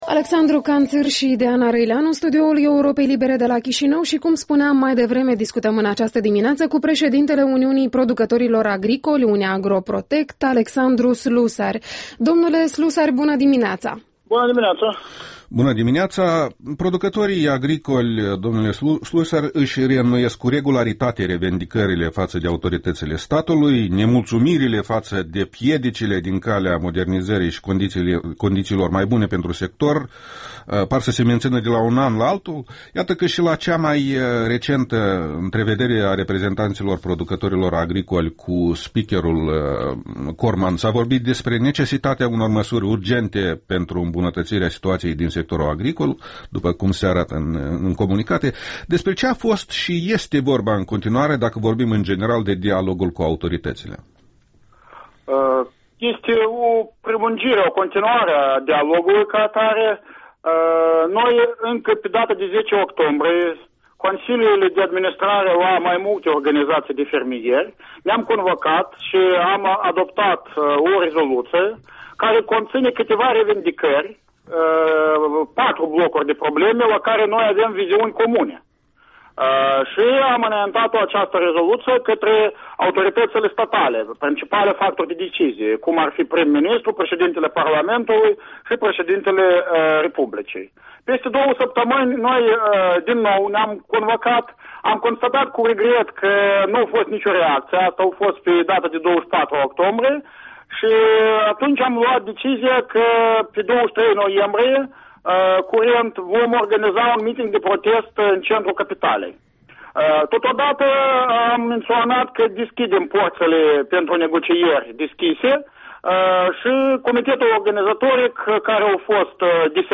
Interviul dimineții: cu Alexandru Slusari